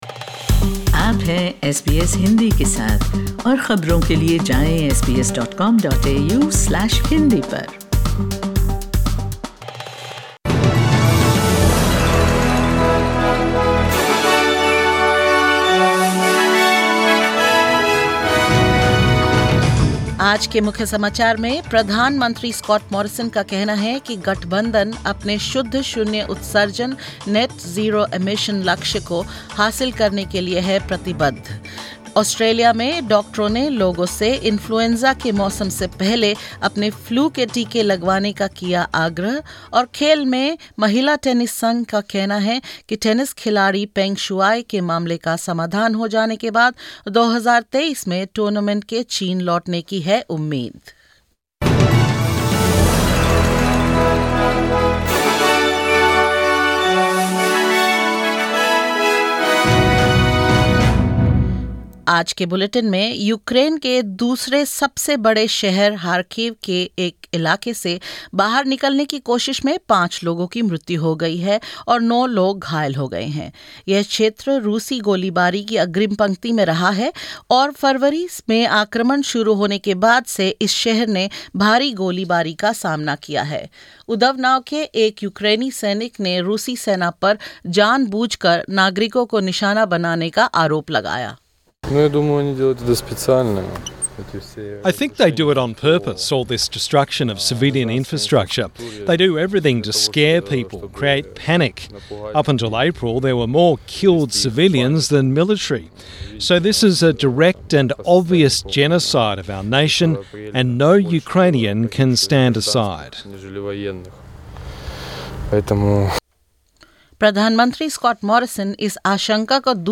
SBS Hindi News 26 April 2022: Doctors urge Australians to get flu shots ahead of potentially devastating influenza season